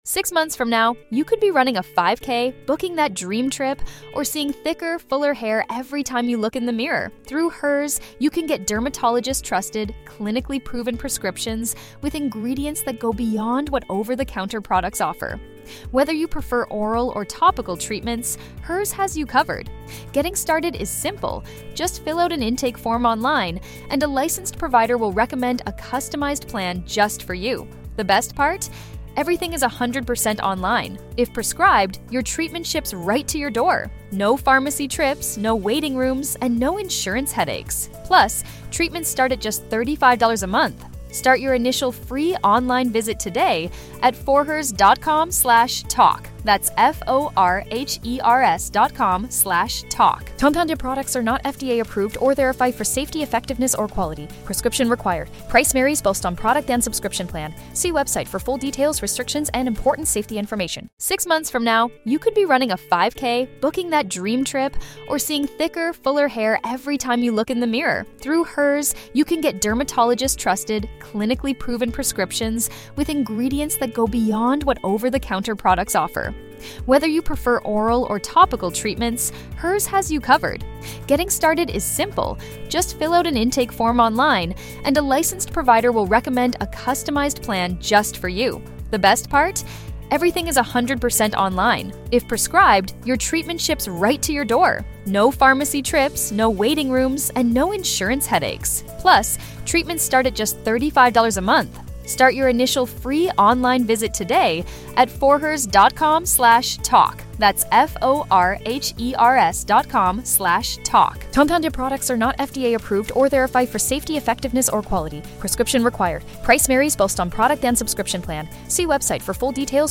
Jason Nash comes by this week to help out as we had some technical difficulties on the road!